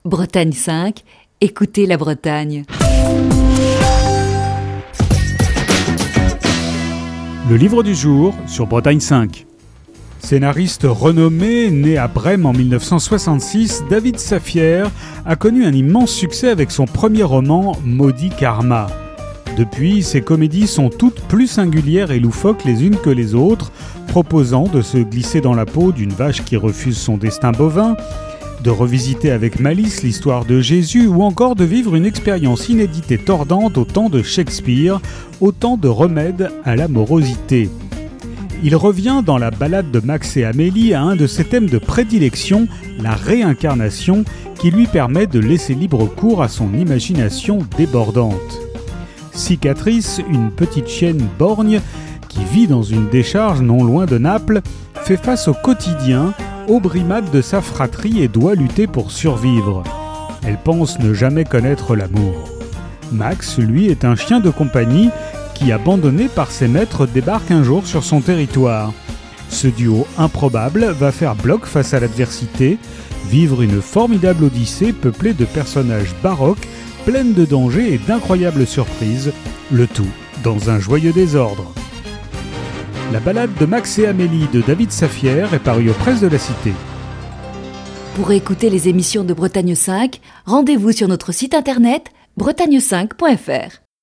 Chronique du 1er juillet 2020.